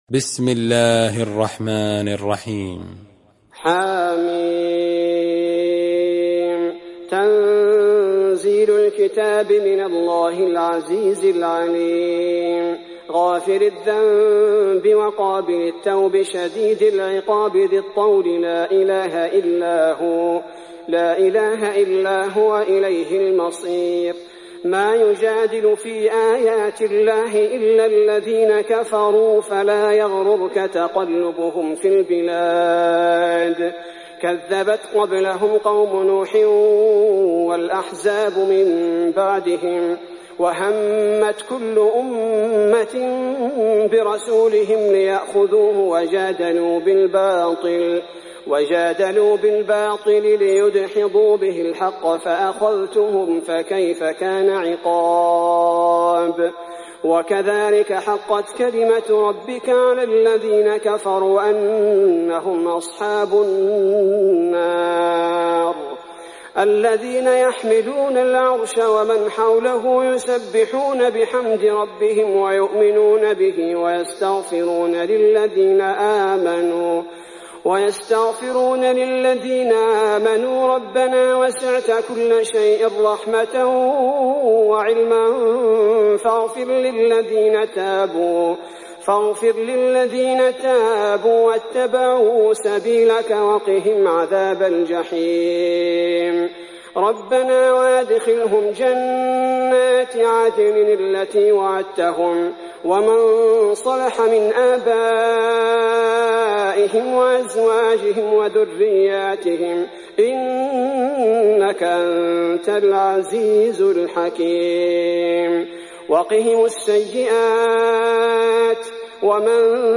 تحميل سورة غافر mp3 بصوت عبد الباري الثبيتي برواية حفص عن عاصم, تحميل استماع القرآن الكريم على الجوال mp3 كاملا بروابط مباشرة وسريعة